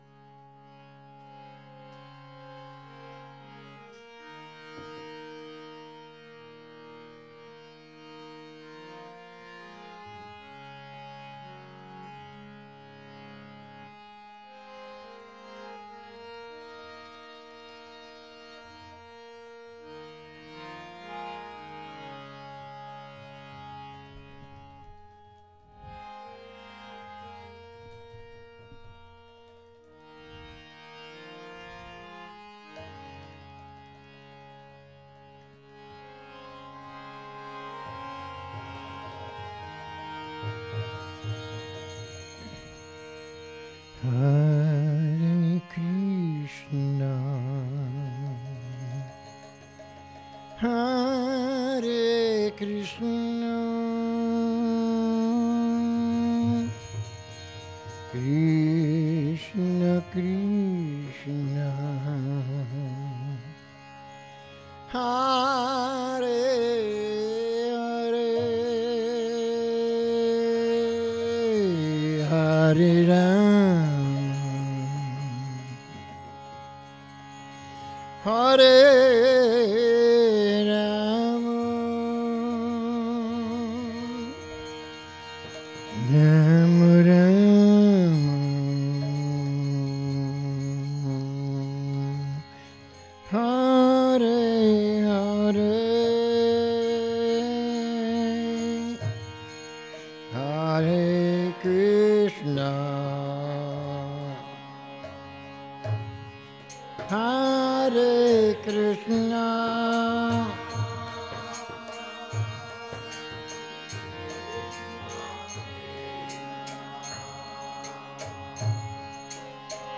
Kírtan – Šrí Šrí Nitái Navadvípačandra mandir